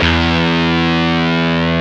DIST GT1-E1.wav